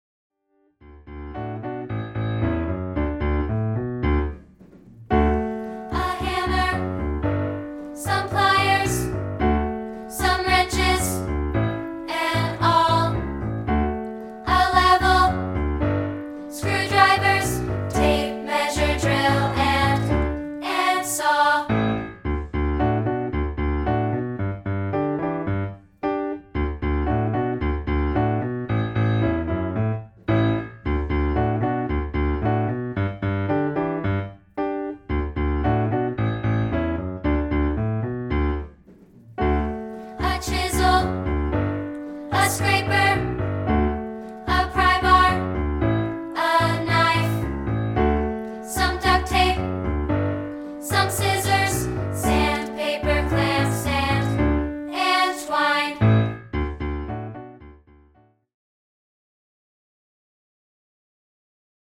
Background Vocals (Low Part)